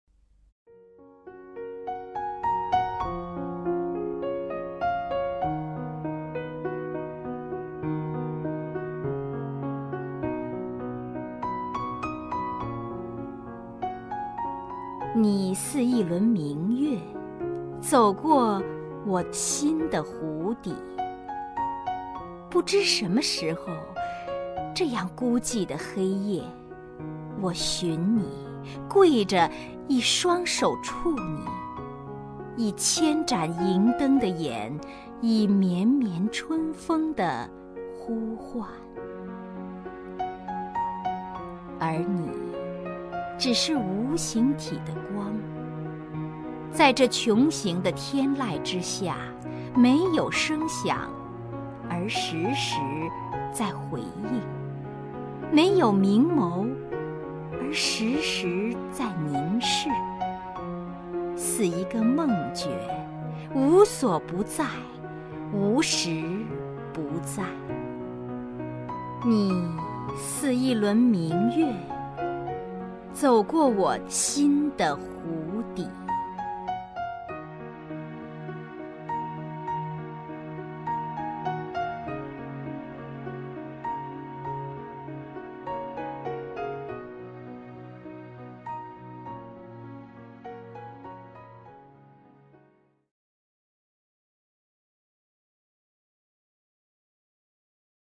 首页 视听 名家朗诵欣赏 王雪纯
王雪纯朗诵：《你似一轮明月走过我心的湖底》(白荻)